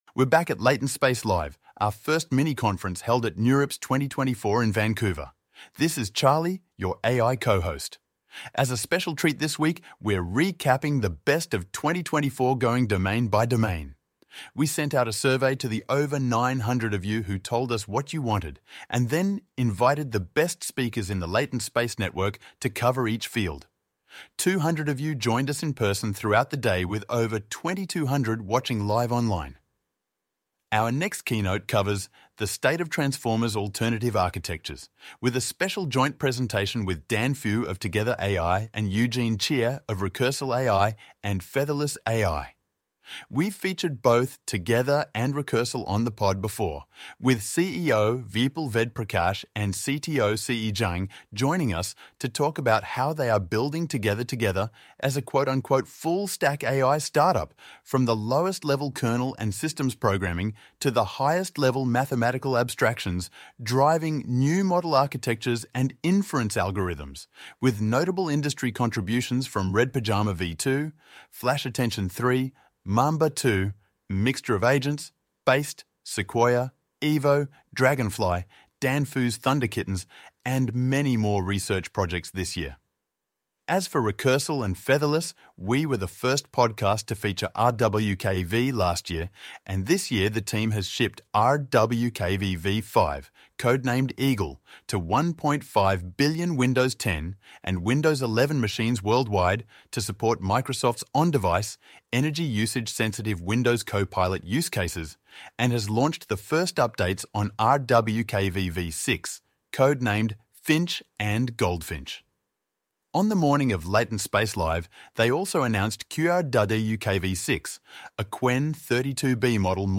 2024 in Post-Transformers Architectures (State Space Models, RWKV) [LS Live @ NeurIPS]